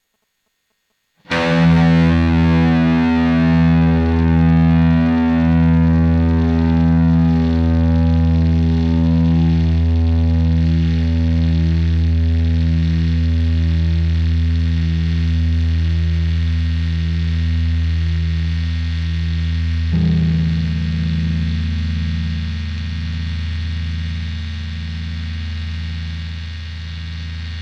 Getting noise and hissing when recording guitar notes
I'm plugging my guitar into that and then plugging that straight into the headphone jack on my laptop, and I'm recording in Audacity. Problem is I'm getting a lot of hissing/noise as the note gets quieter...